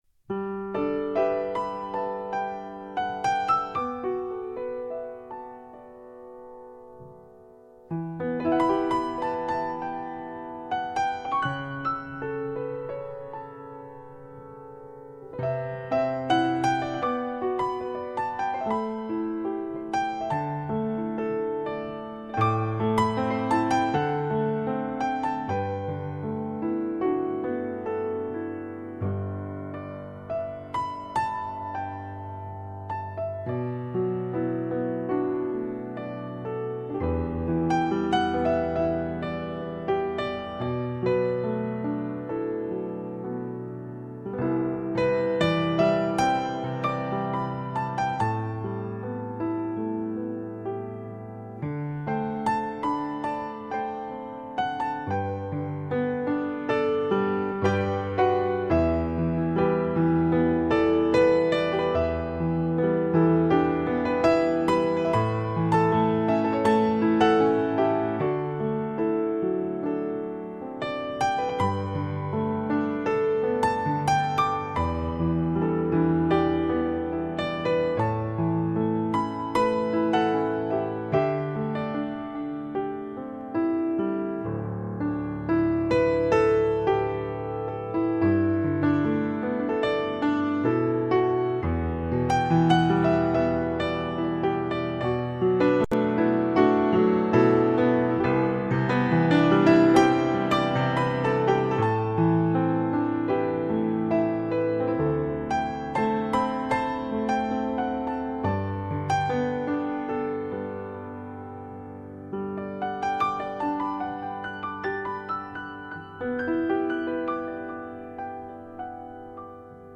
声音靓绝 自然传真 真正示范级发烧三角名琴录音
晶莹剔透的美妙音符 聆听不曾离去的动人旋律
优美的钢琴旋律最易令人心情平复，也最易让人陶醉